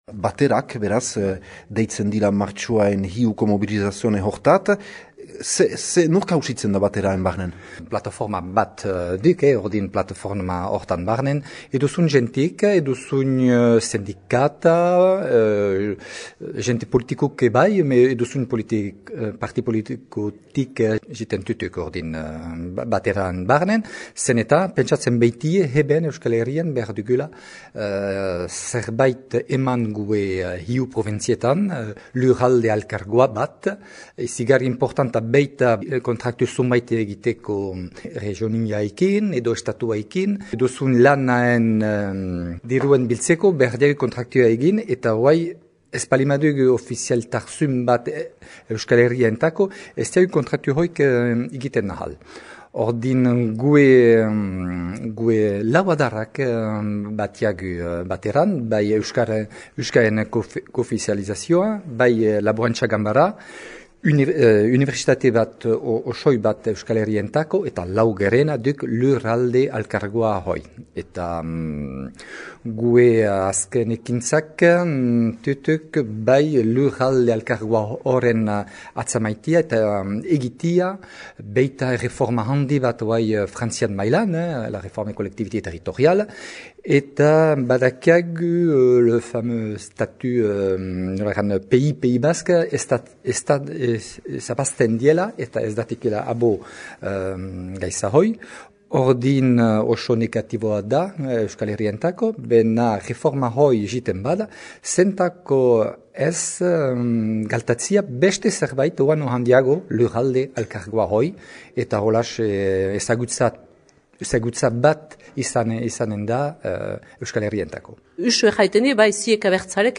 Baterako kideak